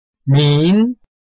臺灣客語拼音學習網-客語聽讀拼-饒平腔-鼻尾韻
拼音查詢：【饒平腔】min ~請點選不同聲調拼音聽聽看!(例字漢字部分屬參考性質)